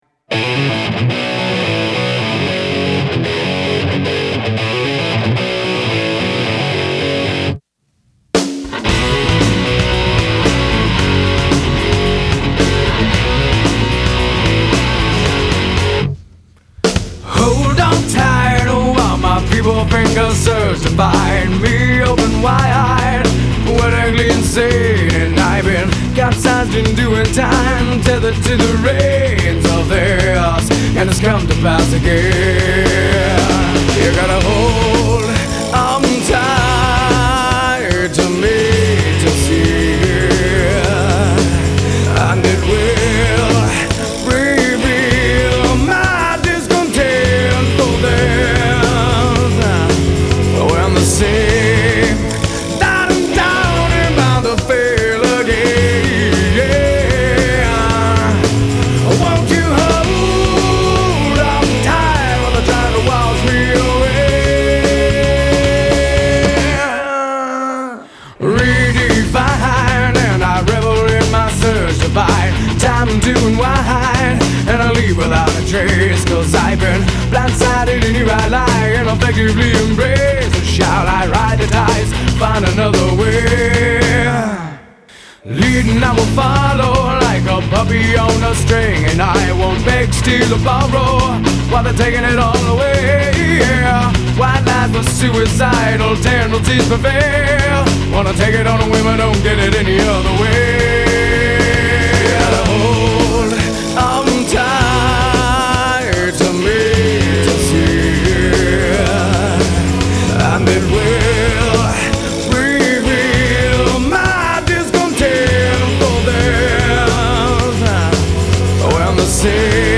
Below are the studio results from those few years.
The bass mix really sucks in all of these though.